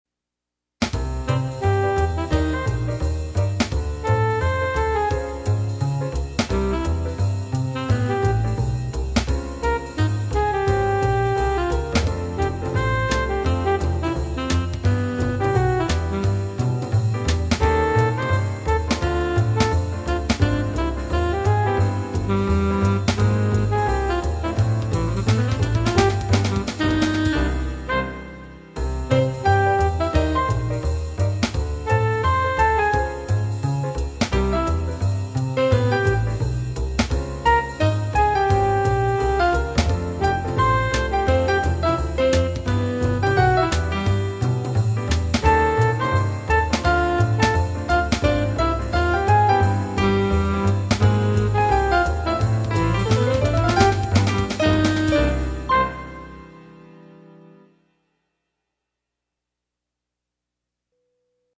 The Chalkman Walk (music for animation) (electronic orchestration). Created for a series of simple Ziggy-like animations about the Insurance industry.